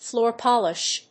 アクセントflóor pòlish